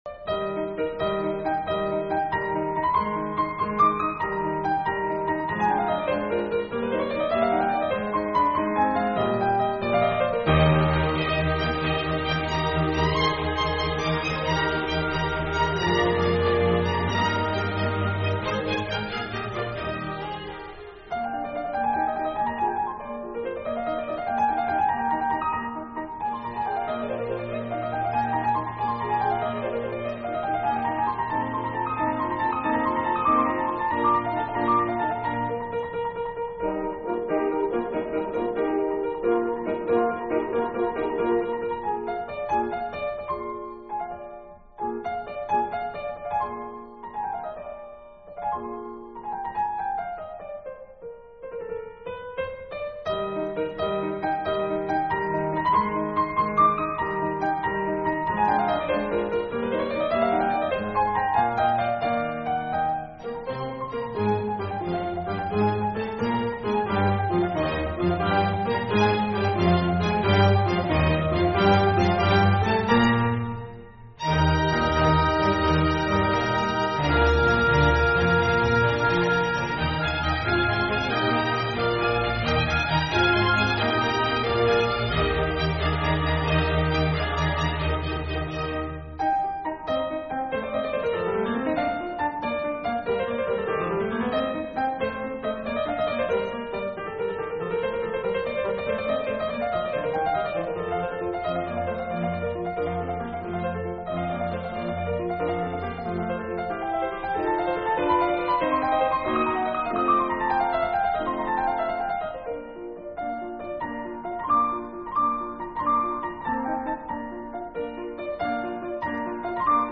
piano concerto
Food for thought as we listen to the rondo finale of Koželuch’s Fifth Piano Concerto.